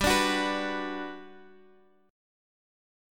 Bm/G chord
B-Minor-G-x,x,5,7,7,7.m4a